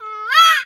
bird_peacock_hurt_01.wav